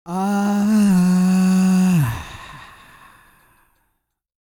E-CROON 3052.wav